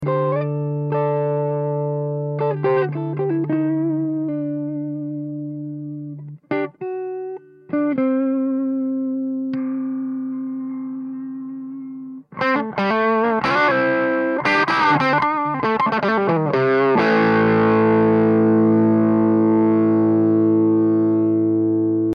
- Copie du son du canal "Blue" du Bogner XTC.